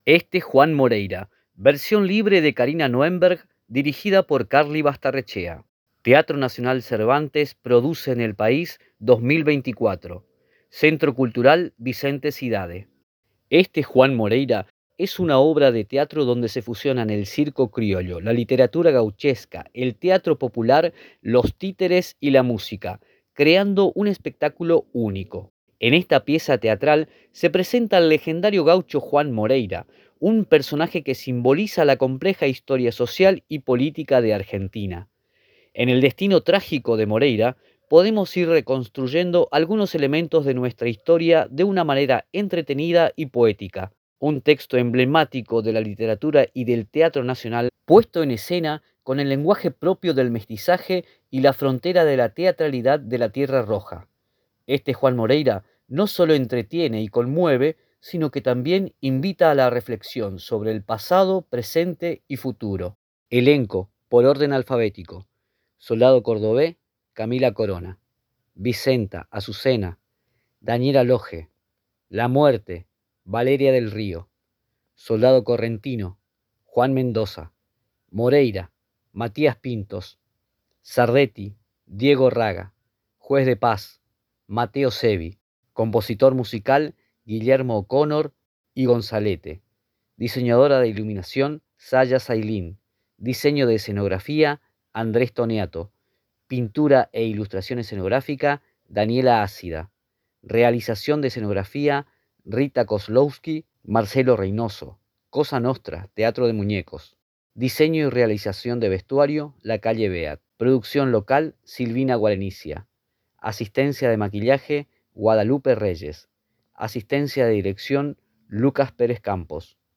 lectura del programa de mano del espectáculo Este Juan Moreira